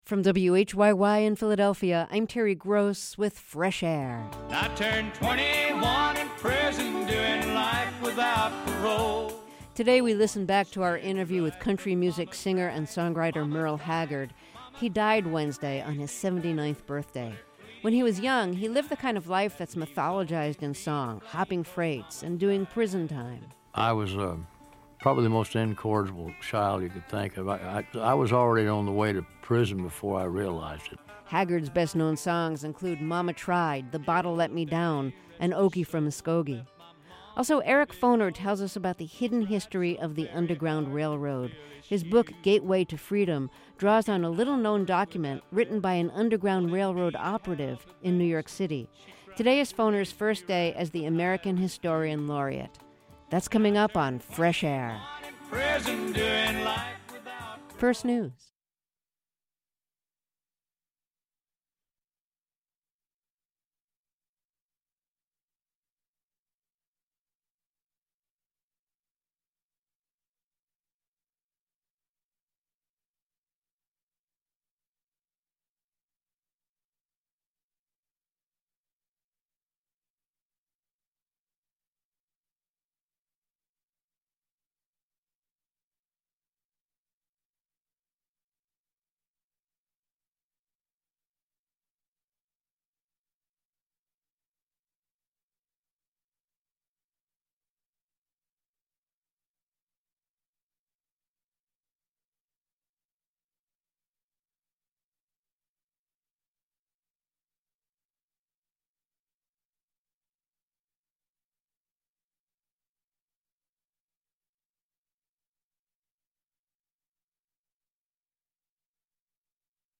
Terry Gross is the host and an executive producer of Fresh Air, the daily program of interviews and reviews.